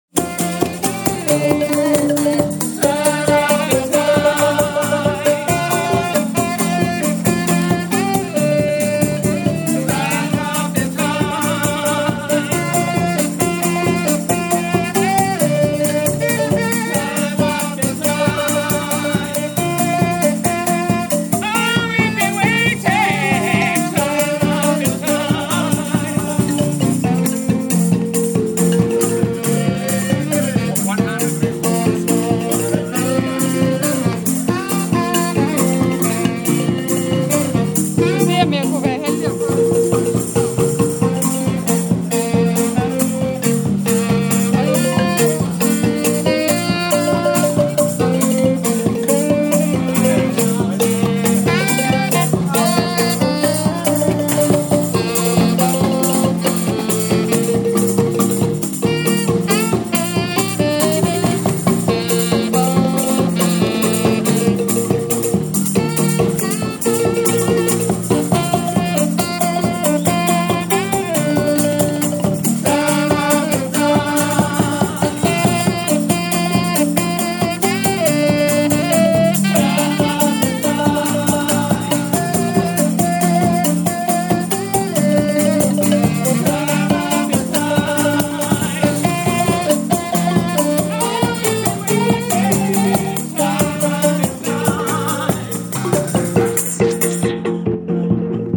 Southafrican rhythms in Cape T